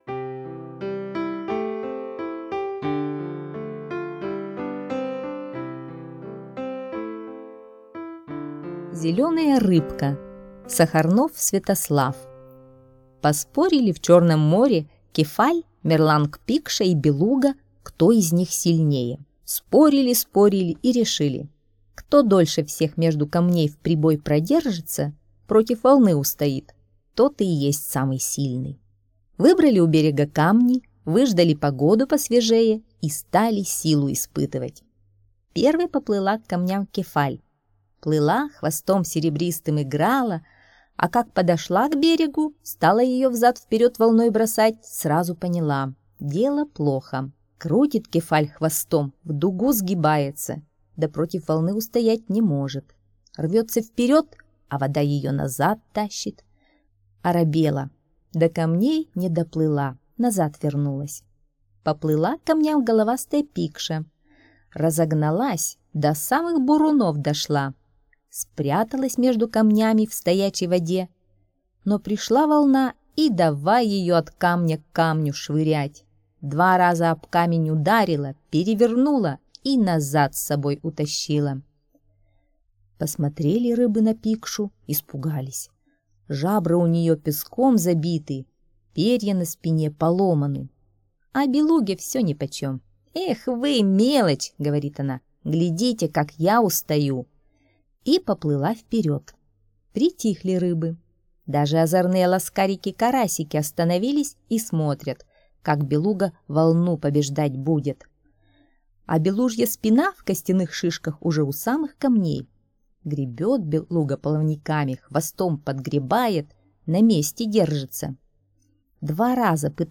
Зелёная рыбка — аудиосказка Сахарнова С.В. Слушайте сказку «Зелёная рыбка» онлайн на сайте Мишкины книжки.